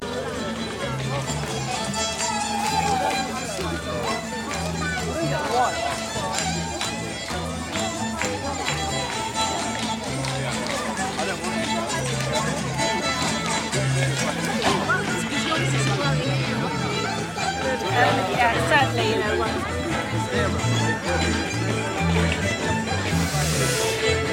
Some sort of dancing at Chippenham folk festival